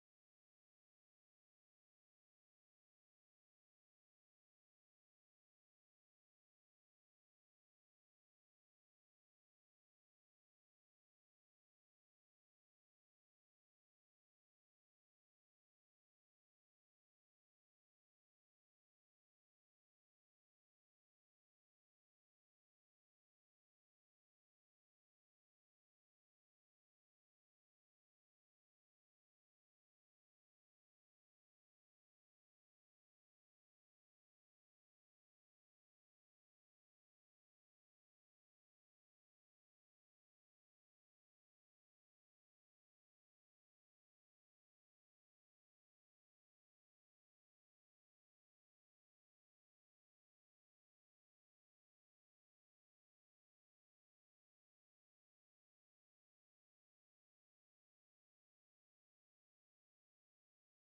Studio Côtier, Frontignan, France.
Guitare
Basse